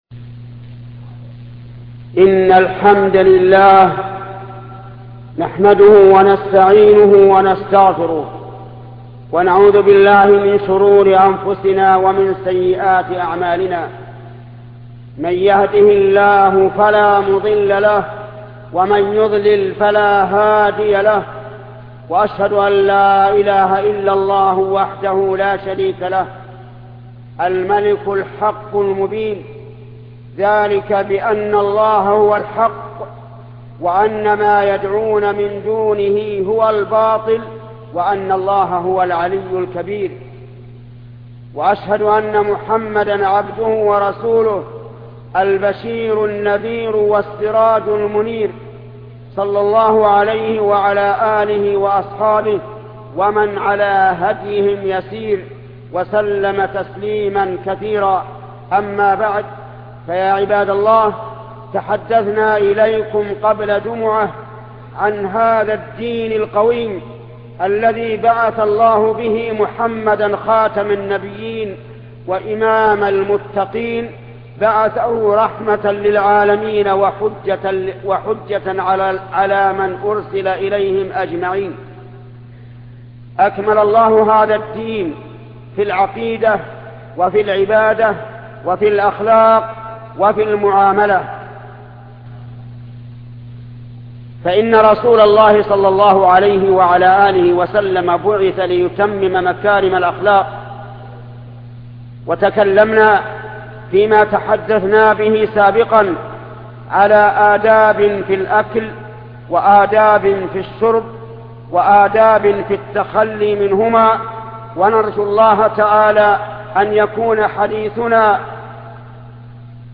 خطبة من الآداب الإسلامية الشيخ محمد بن صالح العثيمين